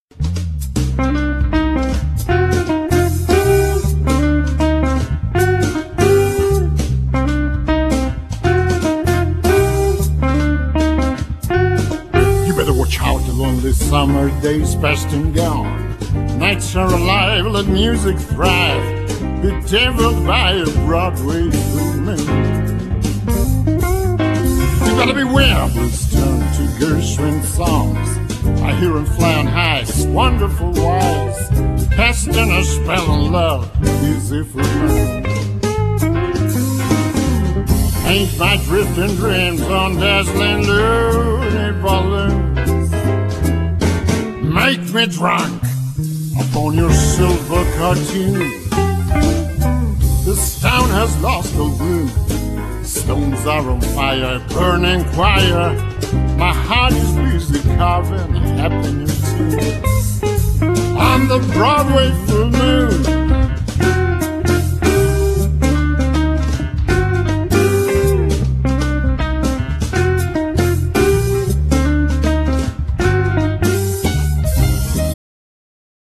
Genere : Jazz & Blues
al piano